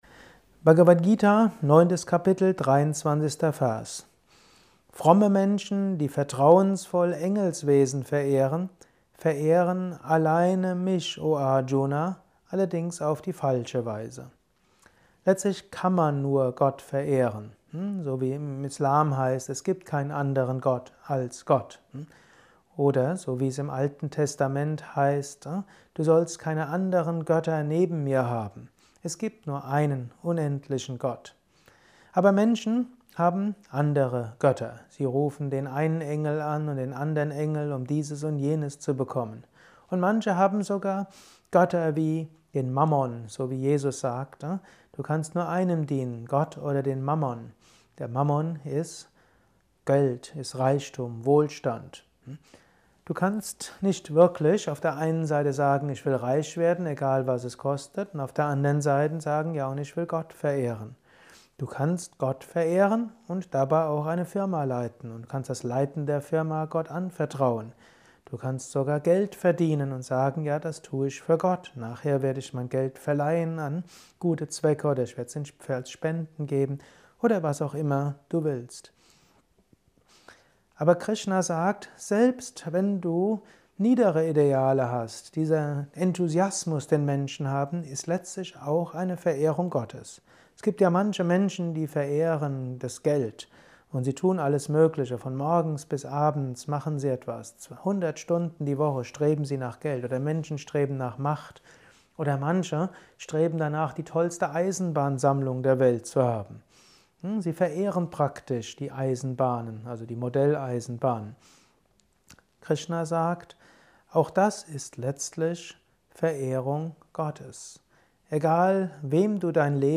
Kurzvortrag